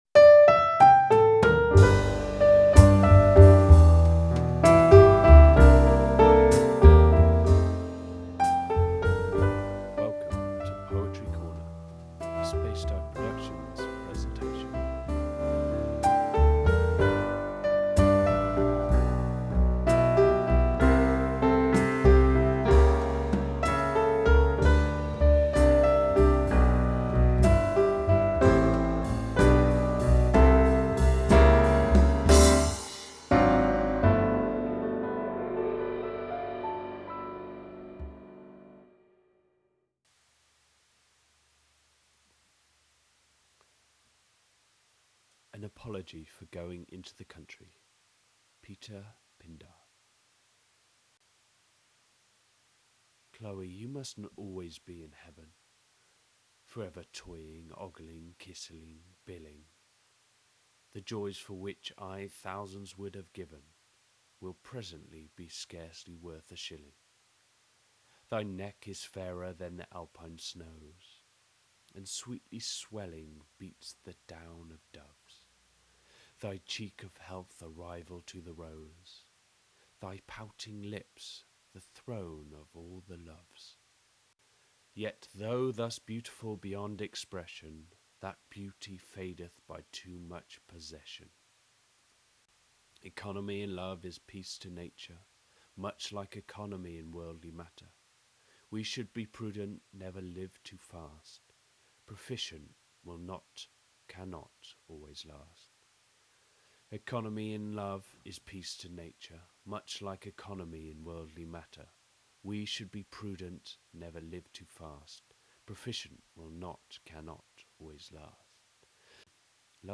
A collection of poems read for you entertainment